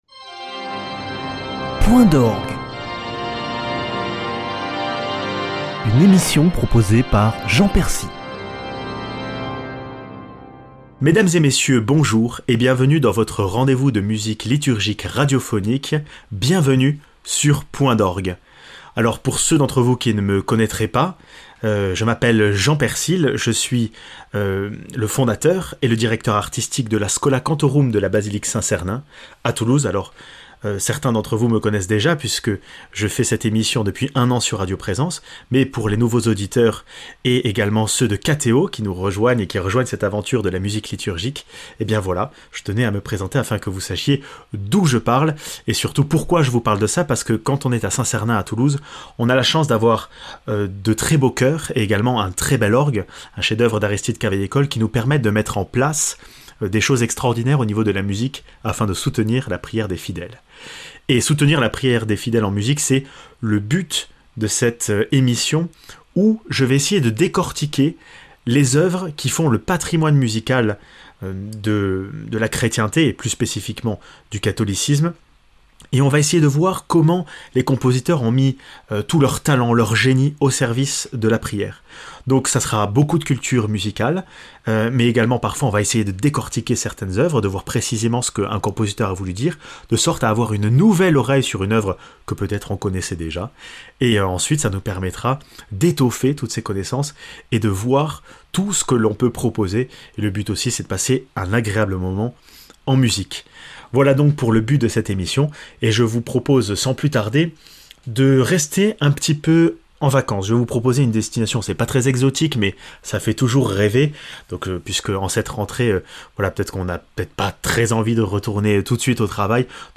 Point d’orgue